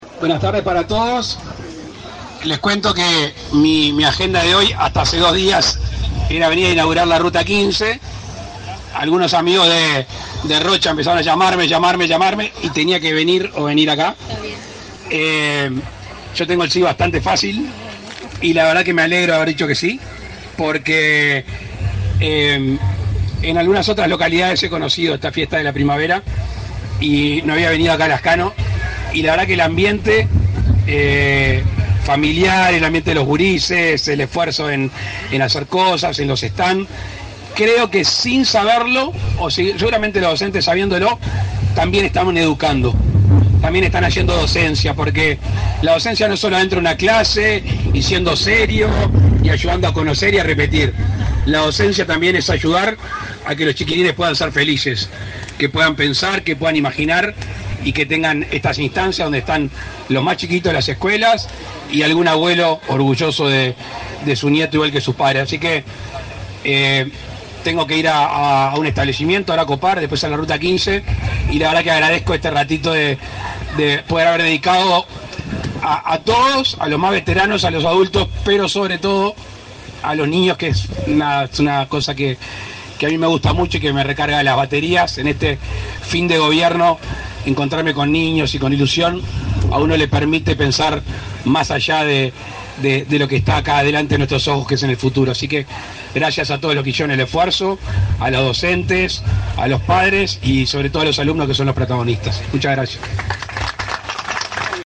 Palabras del presidente de la República, Luis Lacalle Pou, en liceo de Lascano
Palabras del presidente de la República, Luis Lacalle Pou, en liceo de Lascano 08/11/2024 Compartir Facebook X Copiar enlace WhatsApp LinkedIn El presidente de la República, Luis Lacalle Pou, en el marco de su visita por Rocha, concurrió, este 7 de noviembre, al liceo de la localidad de Lascano donde se realizó la Fiesta de la Primavera. En la oportunidad, el mandatario realizó declaraciones.